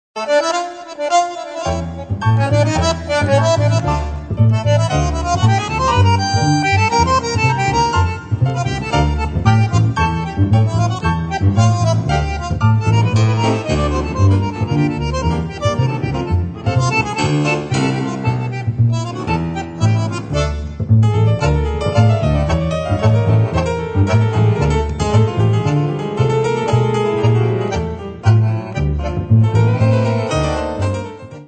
Einige gern gehörte/ getanzte Milonga-Stücke